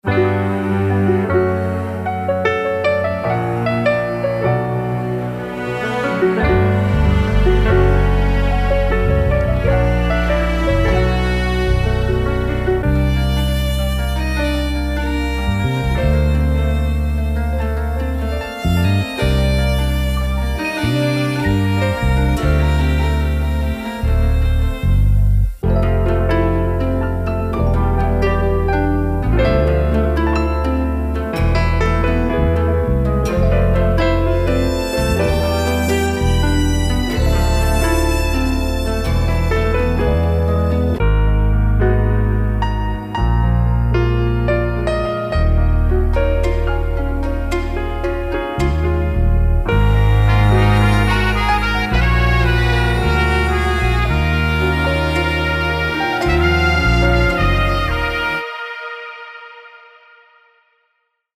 丰富的管弦乐波浪、大胆的铜管和梦幻的木管，与丰富的键盘和旋律相结合，为你带来丰富的 hip hop 感觉。